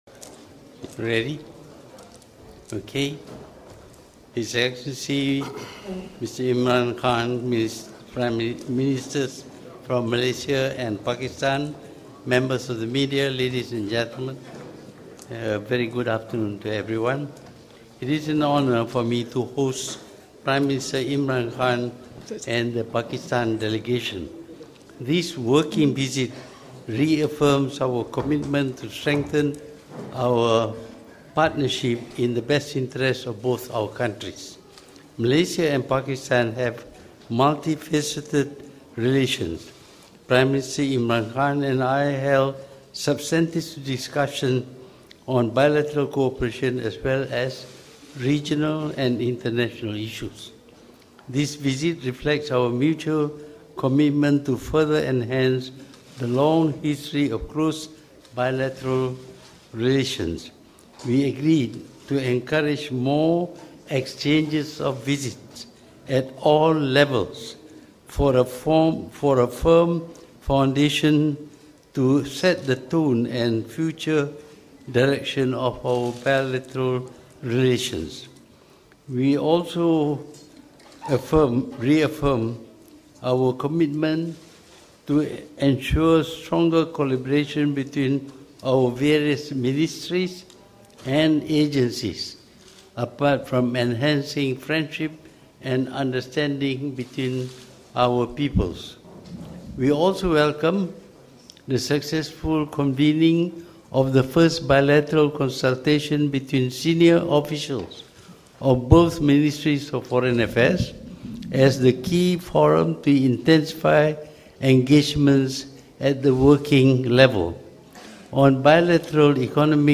Sidang media bersama Dr Mahathir dan Imran Khan